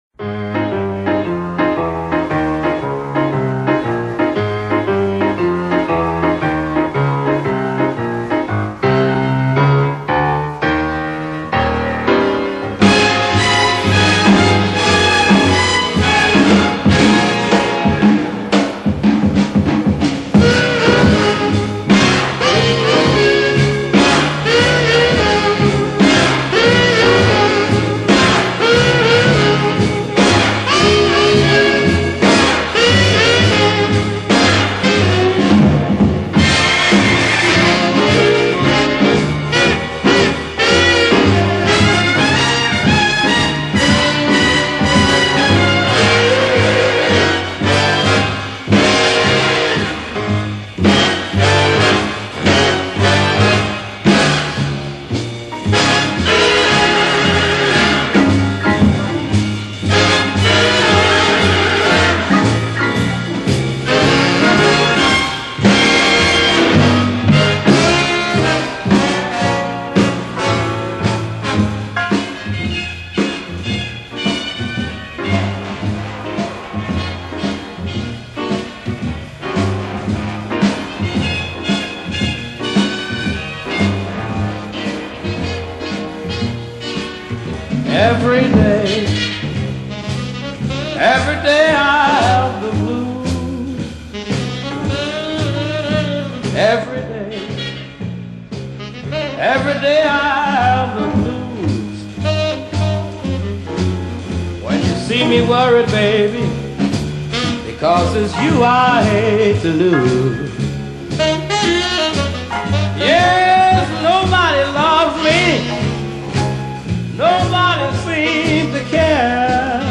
Blues, Electric Blues